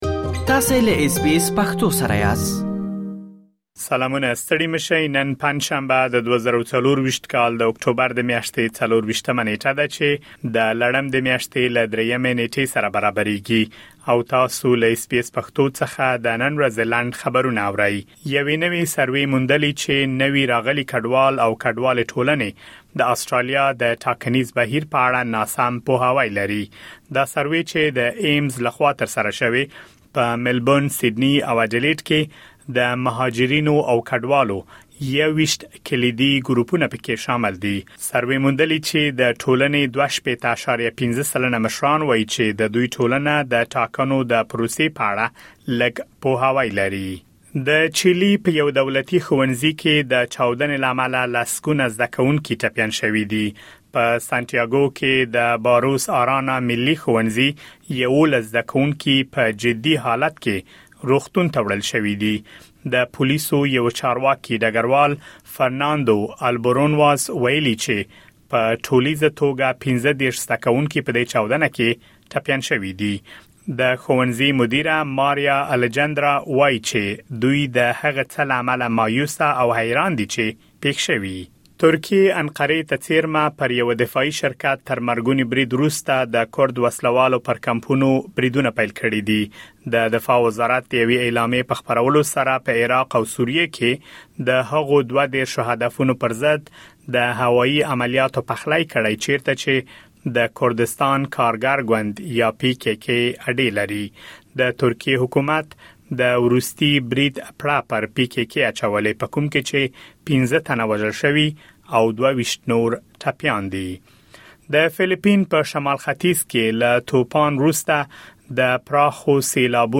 د اس بي اس پښتو د نن ورځې لنډ خبرونه|۲۴ اکټوبر ۲۰۲۴